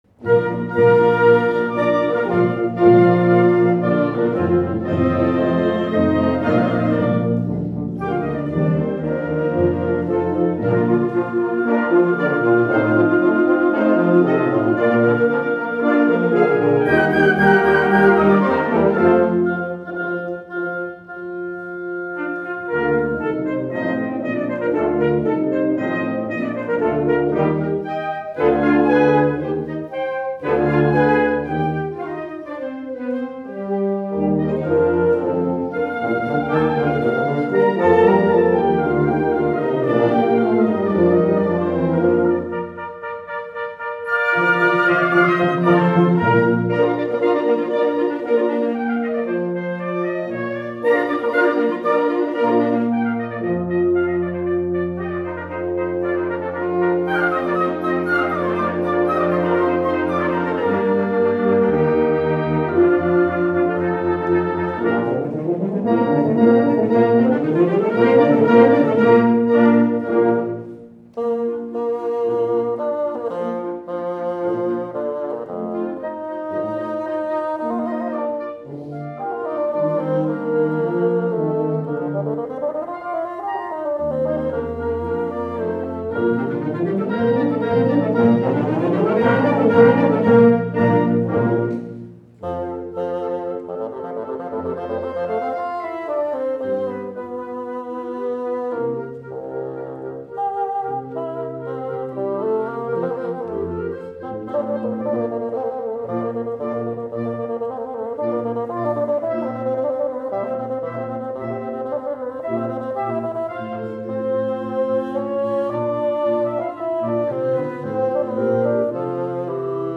Voicing: Bassoon w/ Band